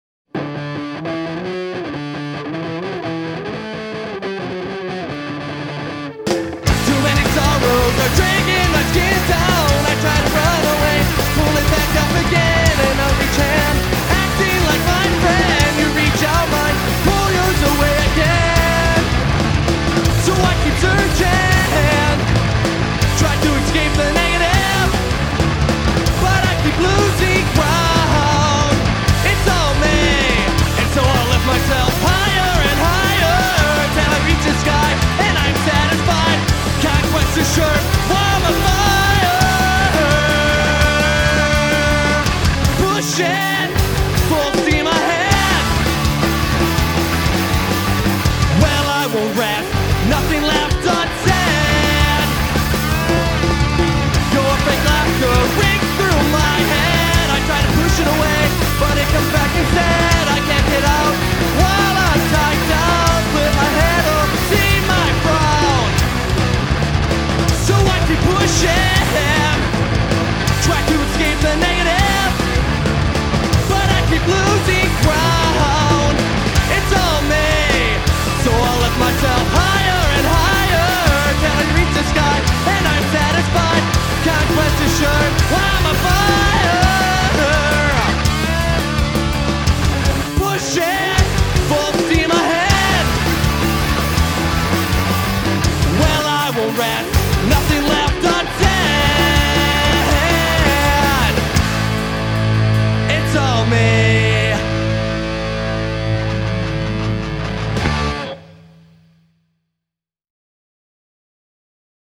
Filed under: Emo , Pop-Punk